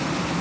jetpack_loop.ogg